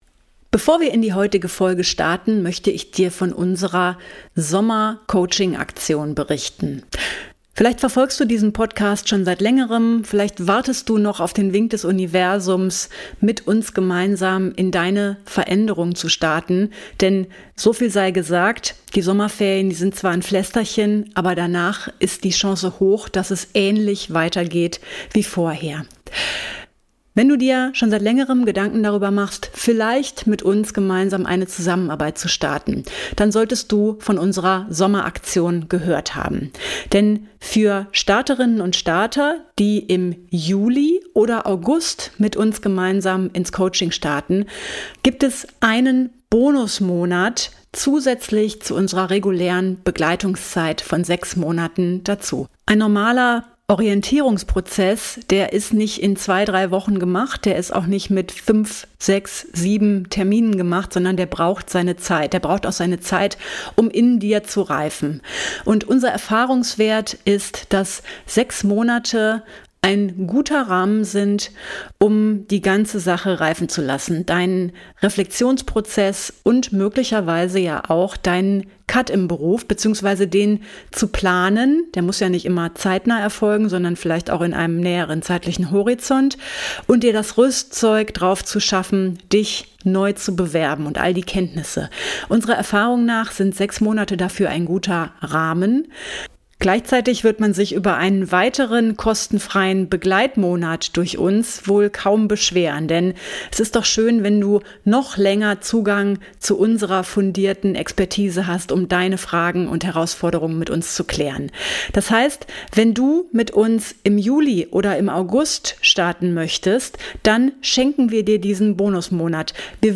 Hier berichten Ex-Lehrkräfte, die mit ihrer Leidenschaft in anderen Branchen Fuß gefasst haben und geben wertvolle Impulse und Infos für deinen Weg in die berufliche und persönliche Freiheit.